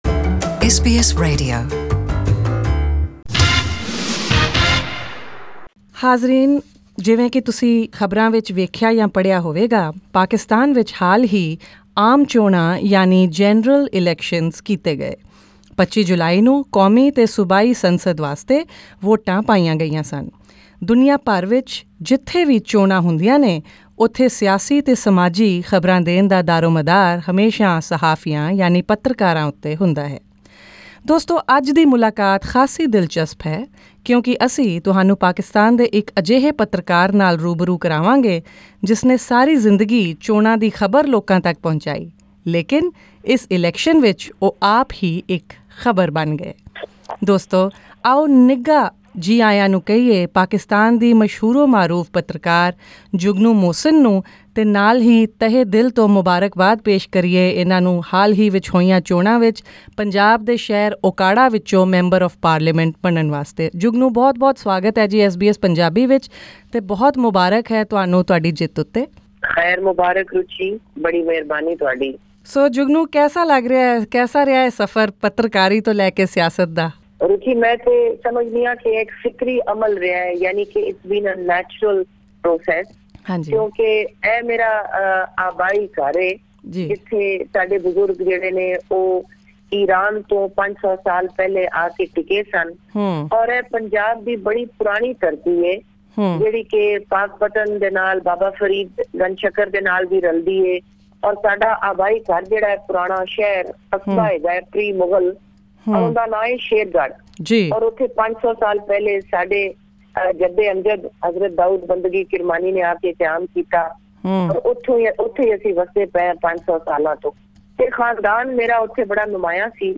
Journalist to politician In an interview with SBS Punjabi , Jugnu talks about her journey from being a law student of the prestigious Cambridge University to being a journalist and now a politician via social work for the poor and needy of her hometown. She also shines a light on the allegations of rigging in the elections and the much-talked-about role of Pakistan’s army in shaping Imran Khan’s victory.